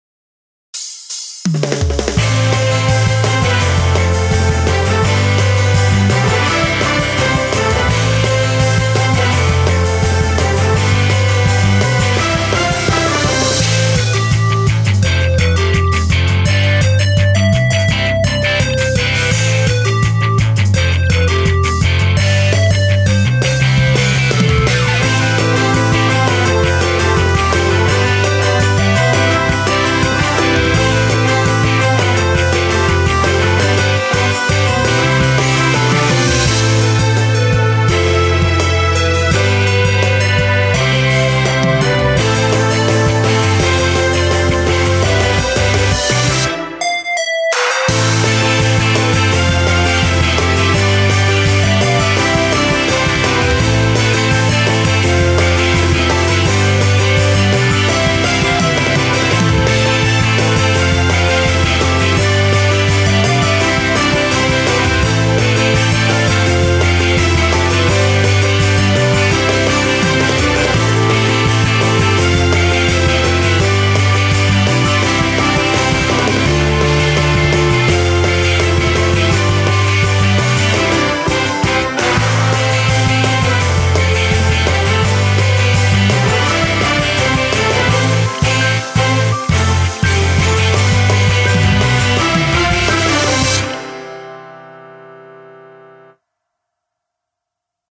インストゥルメンタルショート
BGM